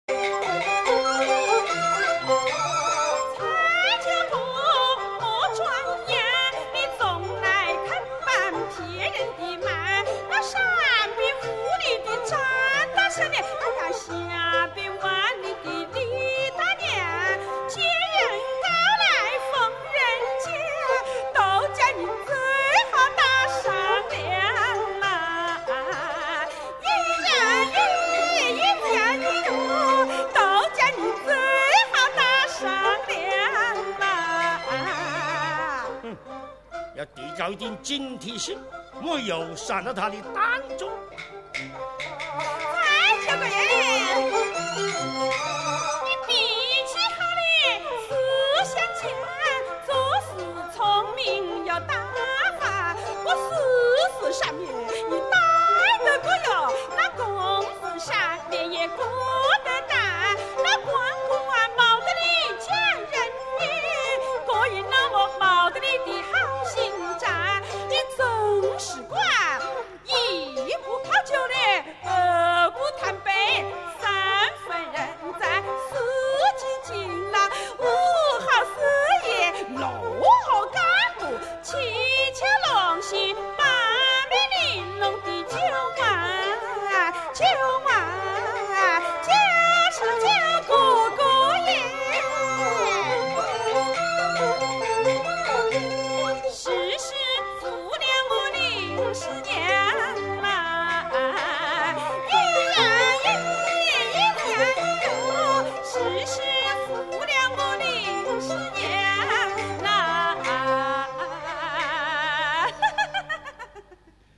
湖南传统花鼓戏